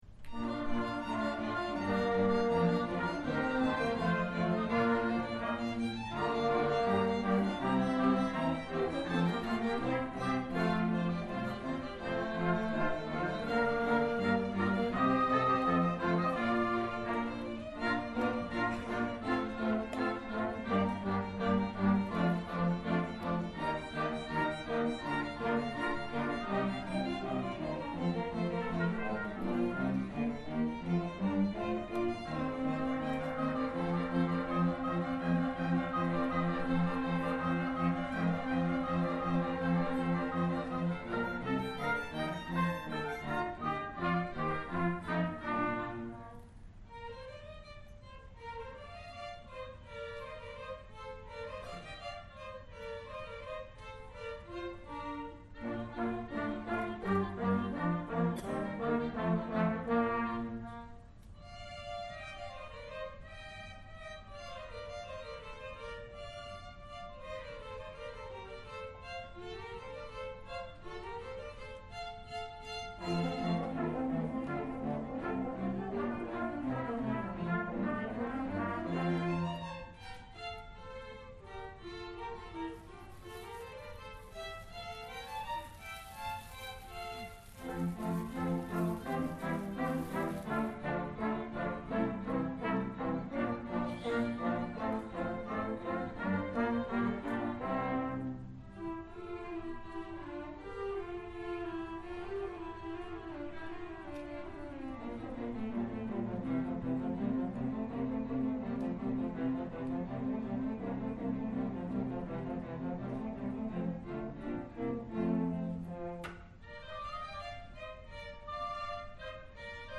The Entry of the Queen of Sheba - Orchestra